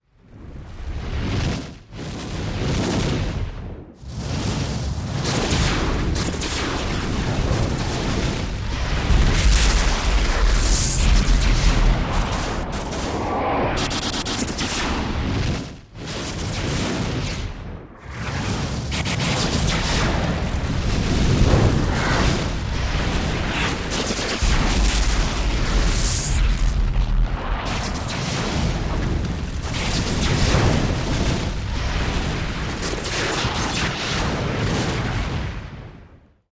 Здесь собраны футуристические аудиоэффекты: от гула двигателей звездолетов до оглушительных взрывов в глубоком космосе.
Взрывы и нападение космического корабля на военные объекты